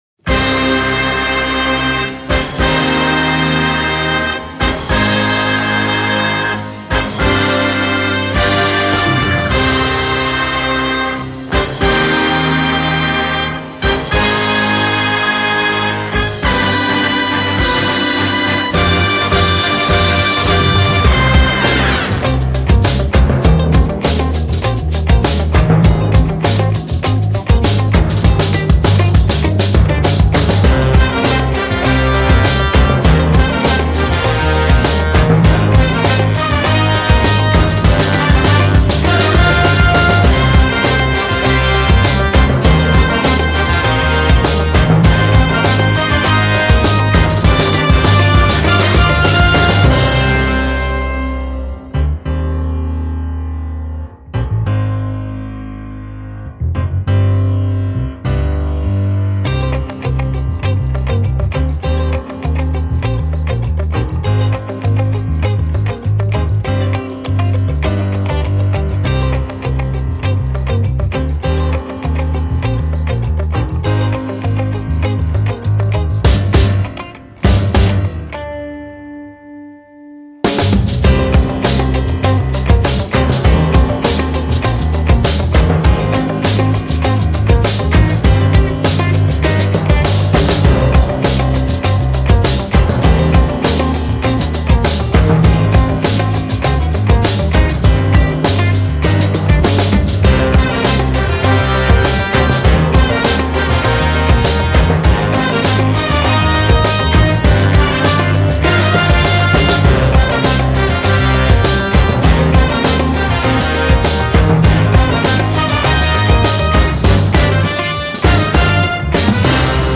jazzy-funky-rock-hip-hop
C’est groovy, fun et vraiment très cool.